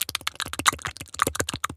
dog_lick_smell_02.wav